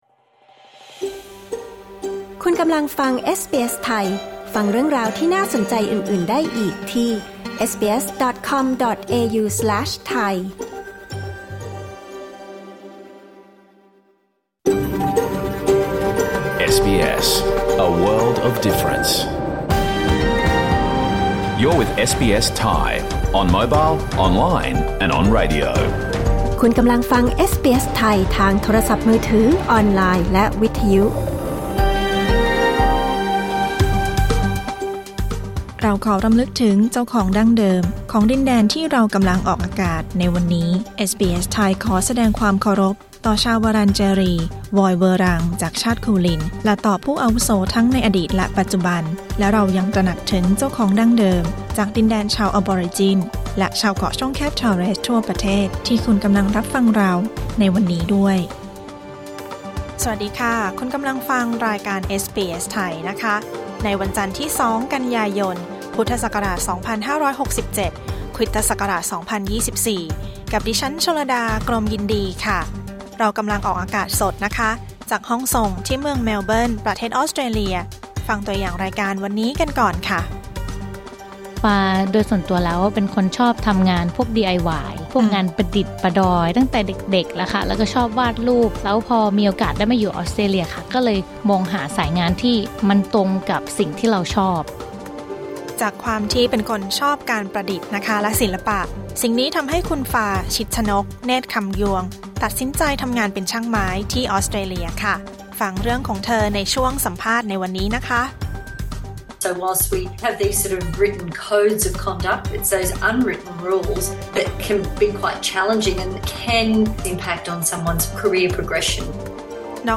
รายการสด 2 กันยายน 2567